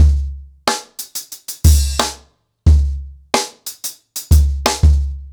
CornerBoy-90BPM.29.wav